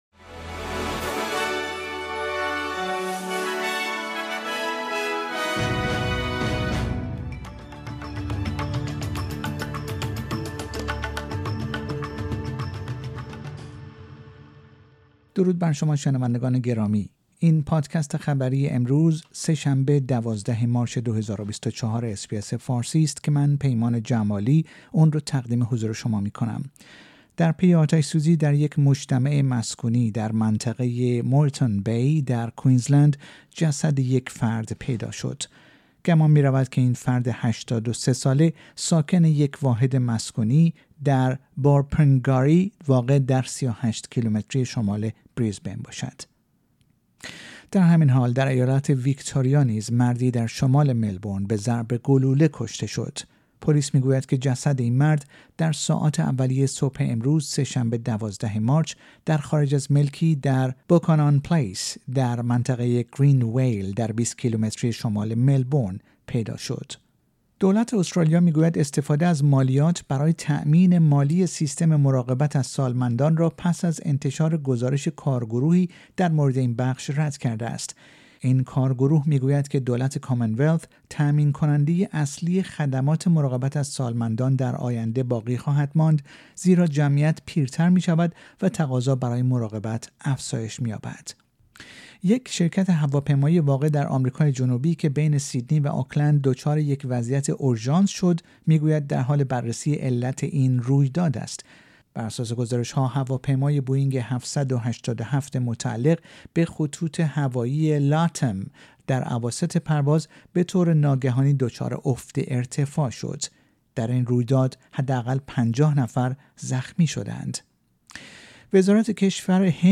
در این پادکست خبری مهمترین اخبار استرالیا و جهان در روز سه شنبه ۱۲ مارچ ۲۰۲۴ ارائه شده است.